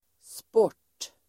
Uttal: [spår_t:]